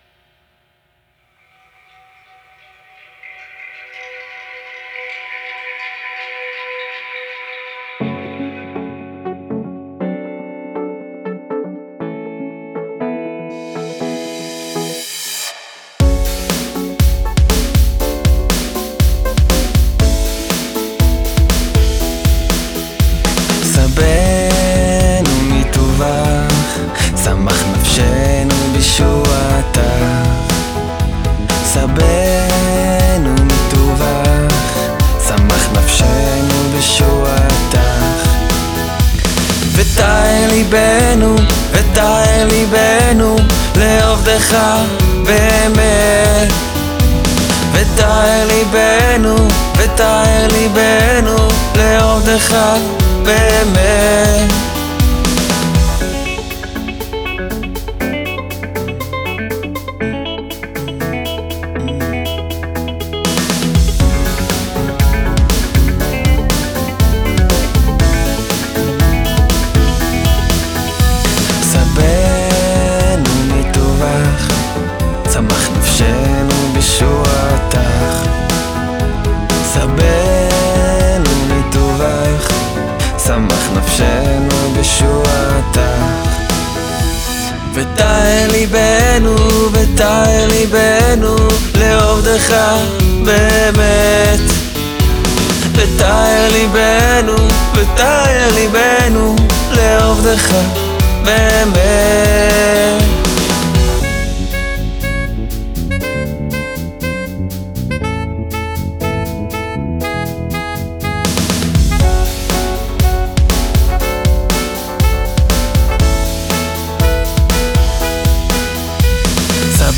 קצת ג"זי הלחן חמוד קליט וחוזר על עצמו